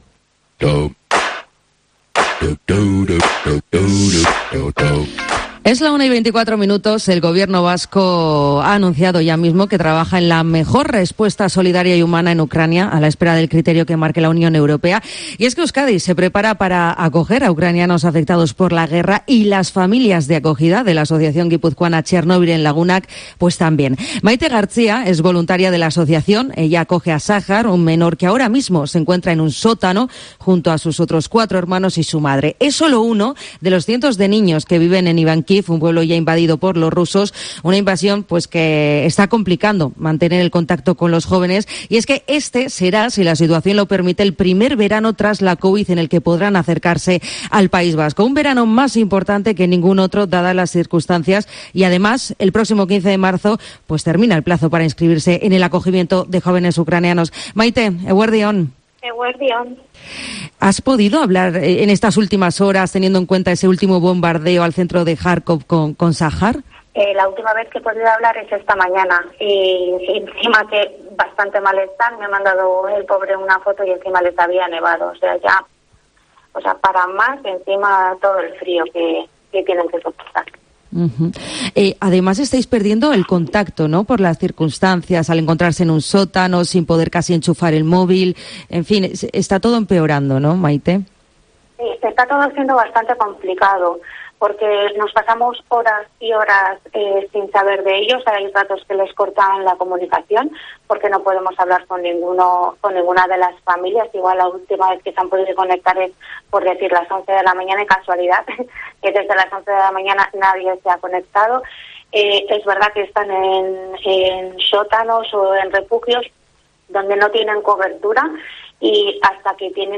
en COPE Euskadi